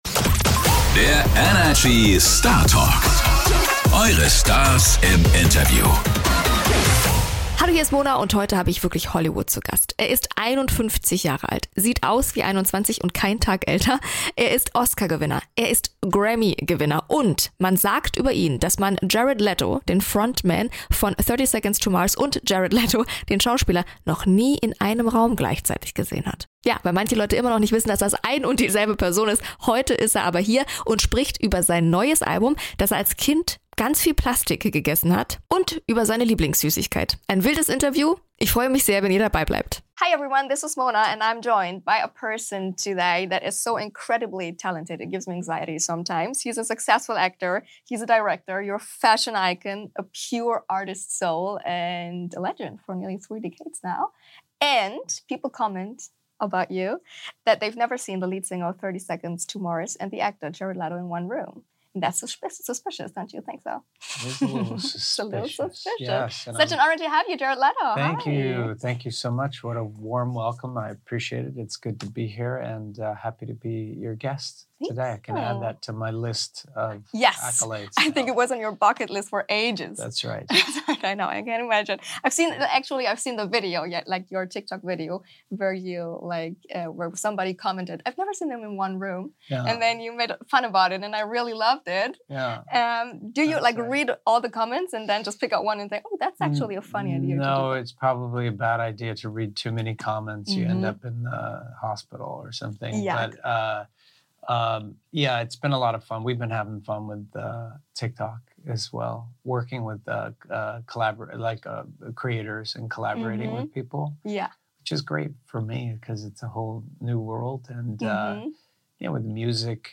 Im Interview hat er uns mit seiner lustigen und etwas verrückten Art verzaubert und teilweise auch mit mehr Fragen als zuvor zurück gelassen. Mit welcher Süßigkeit er seinen neuen Song "Stuck" vergleicht und warum er mit seinem neuen Album eigentlich gar nichts sagen möchte, hört ihr in dieser neuen Startak-Folge.